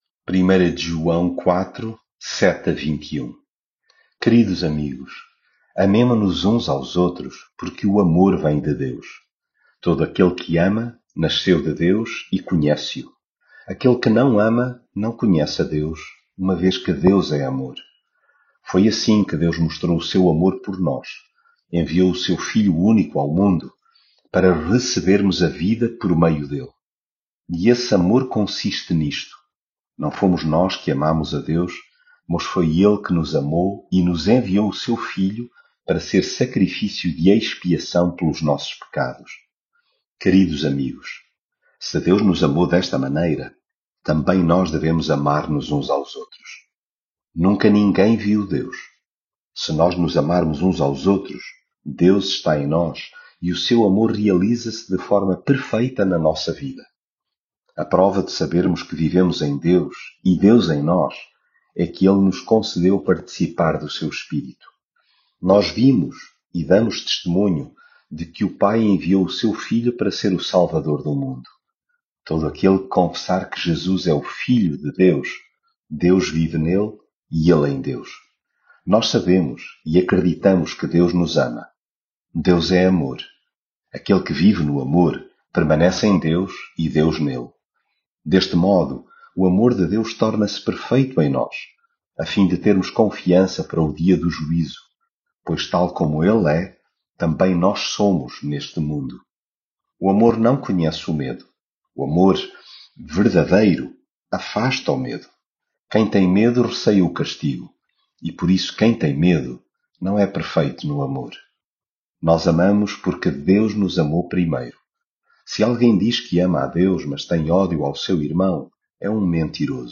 leitura bíblica